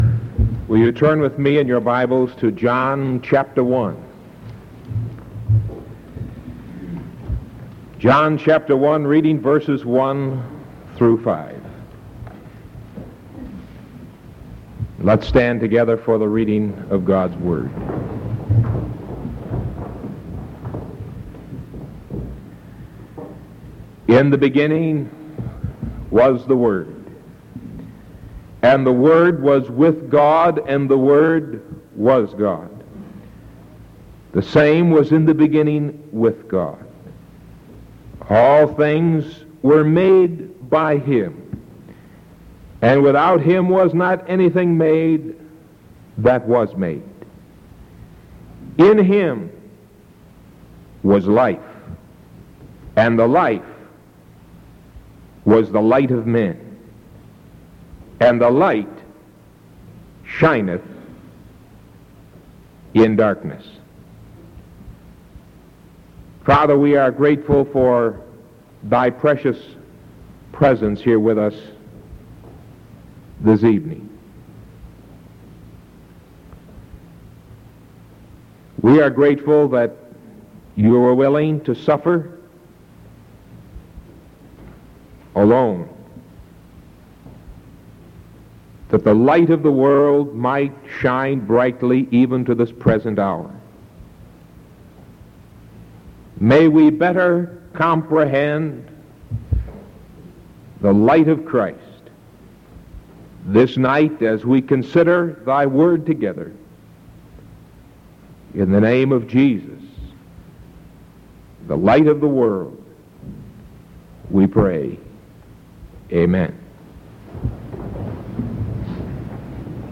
Sermon July 8th 1973 PM